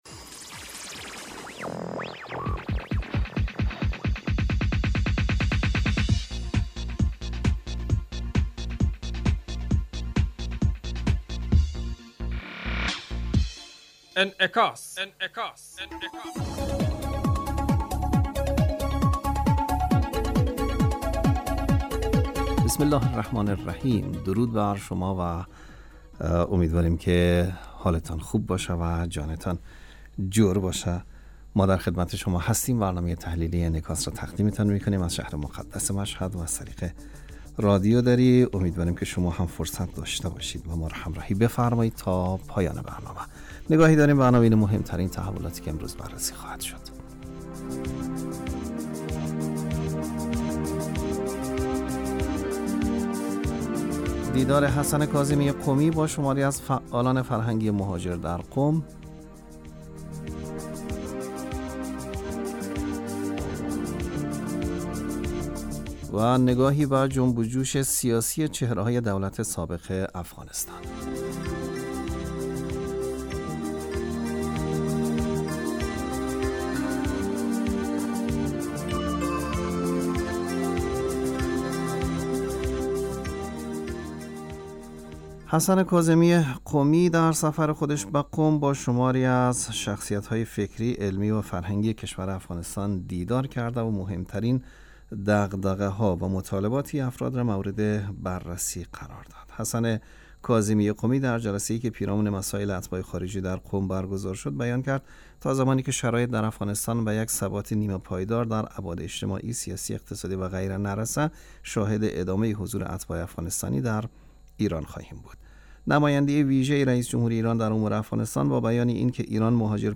برنامه انعکاس به مدت 35 دقیقه هر روز در ساعت 06:50 بعد ظهر (به وقت افغانستان) بصورت زنده پخش می شود.